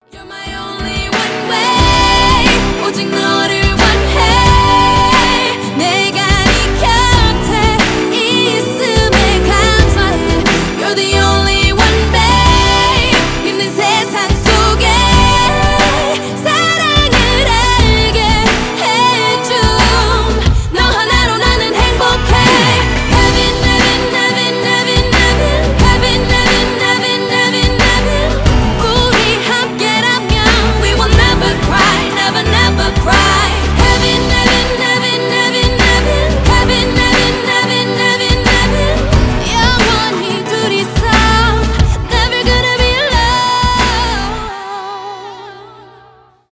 k-pop
красивый женский голос , rnb , поп